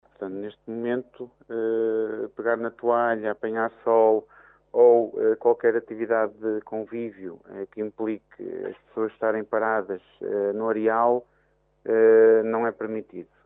Pegar na toalha, estendê-la no areal e apanhar sol é algo que por enquanto ainda não é permitido fazer, como sublinha o Comandante do Porto de Caminha.